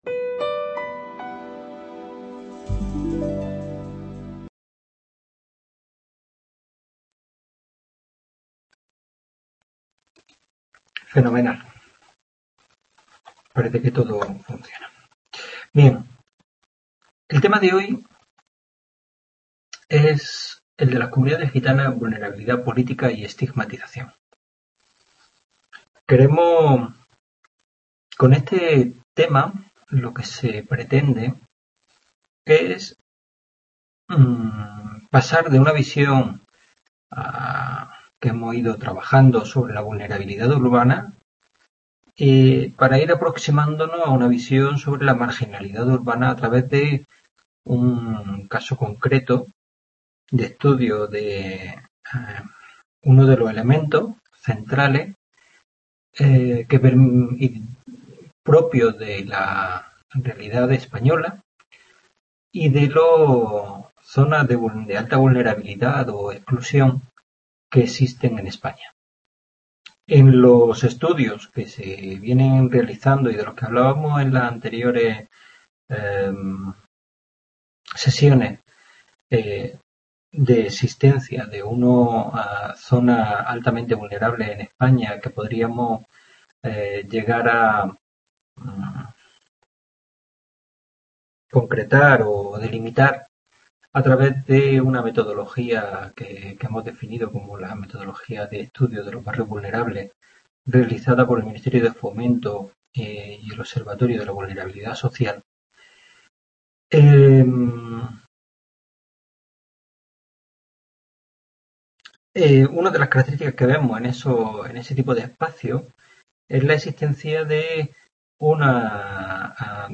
Tutoria 5 delincuencia y vulnerabilidad